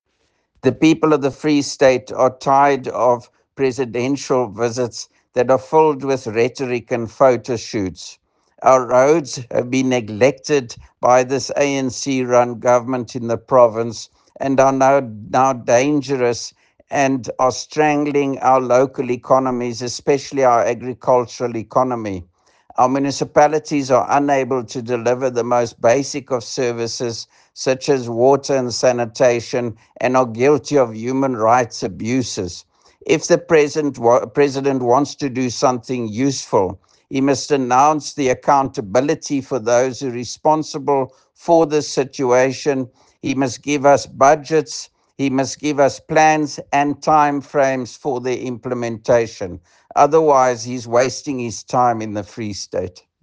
Issued by Roy Jankielsohn – DA Free State Leader